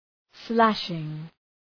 Shkrimi fonetik {‘slæʃıŋ}